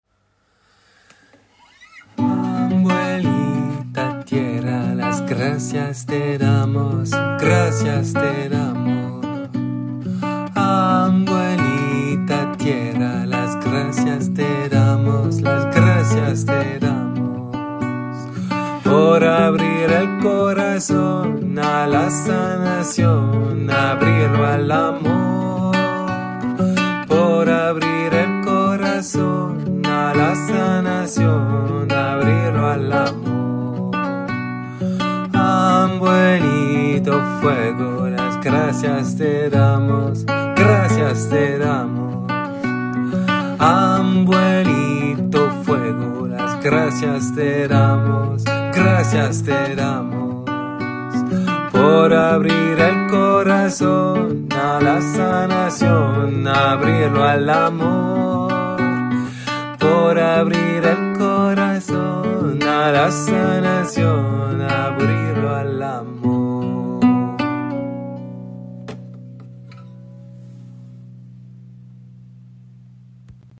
Voici quelques chants pratiqués en atelier.